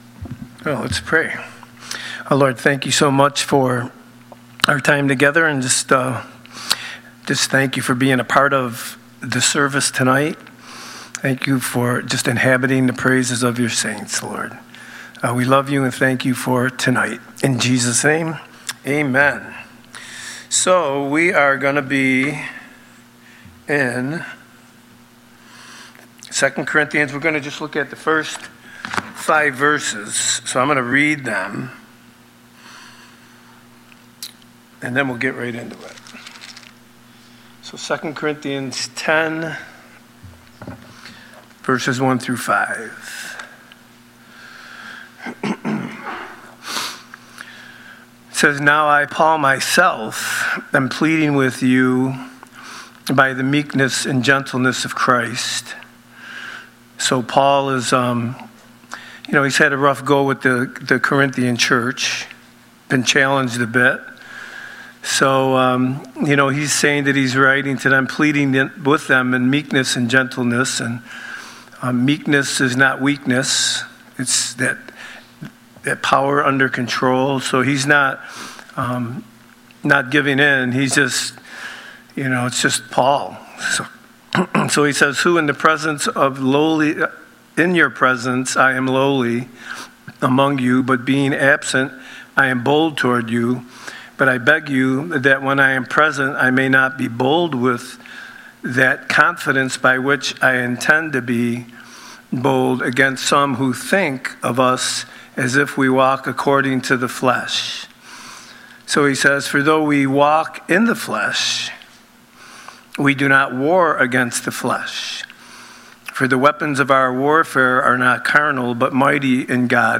These are the teachings that have taken place in the Sanctuary at Calvary Chapel of Rochester